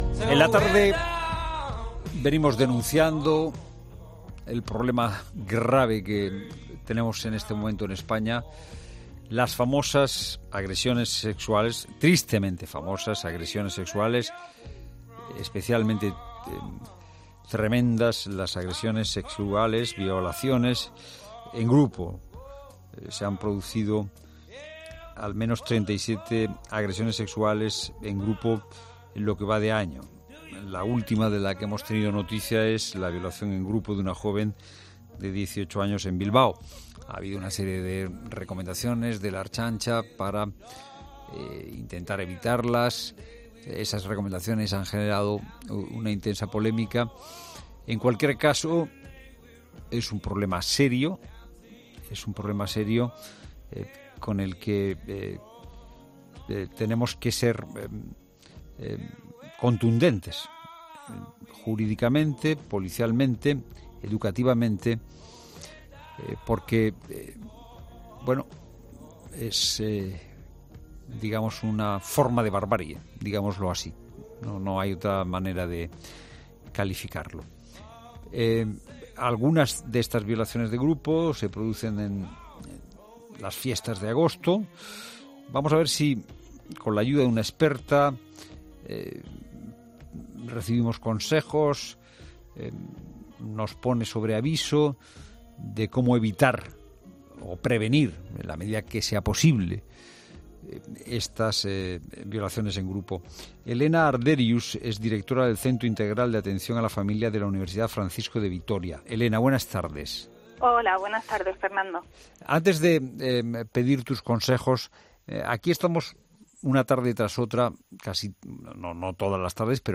Este martes en 'La Tarde' en COPE